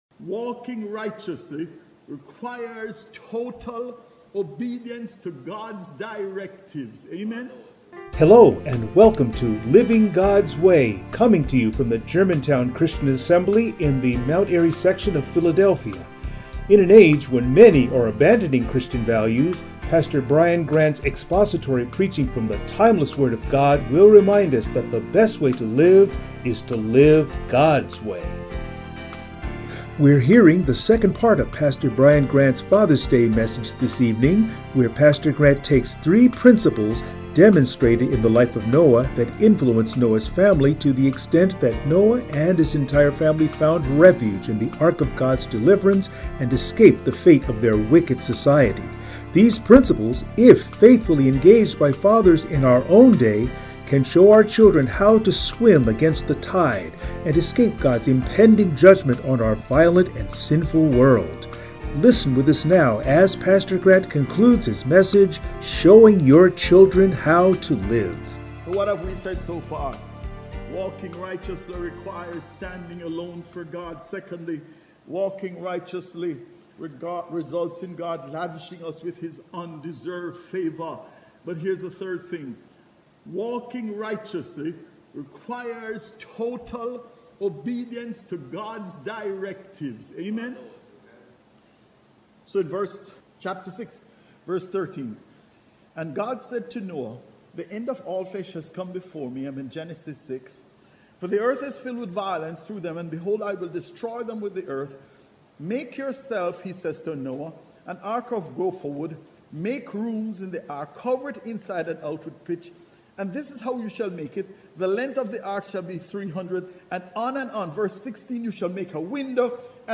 Passage: Genesis 6:1-22 Service Type: Sunday Morning